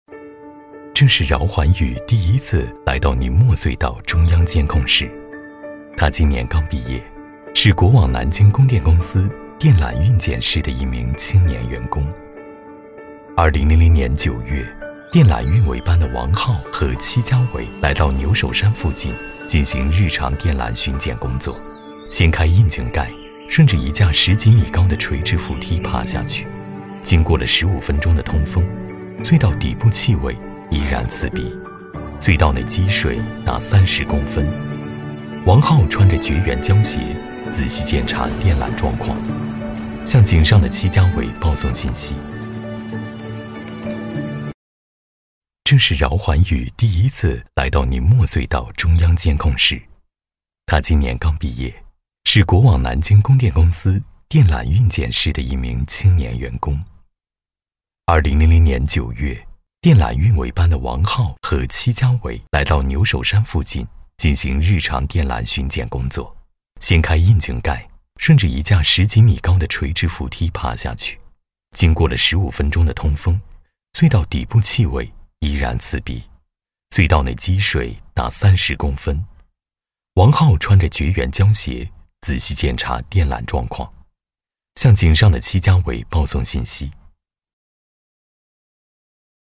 男国148年轻大气配音-新声库配音网
2 男国148_专题_人物_追光_讲述 男国148
男国148_专题_人物_追光_讲述.mp3